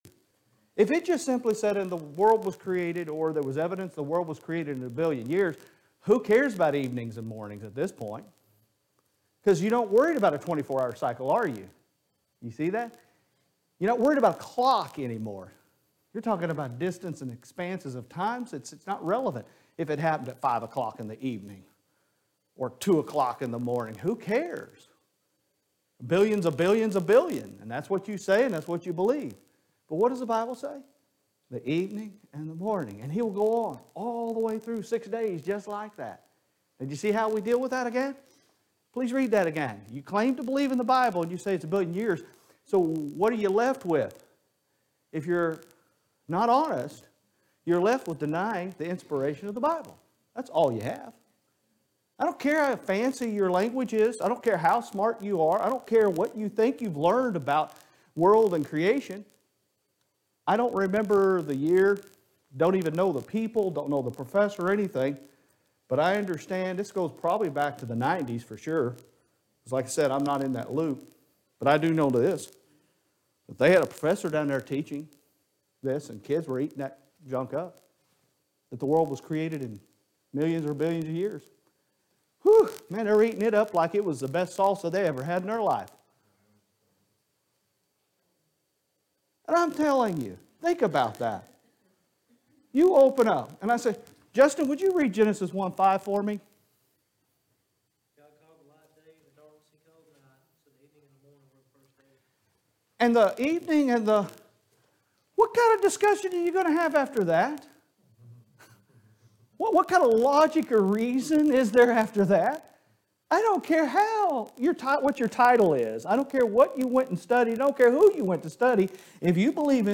Sunday AM Bible Study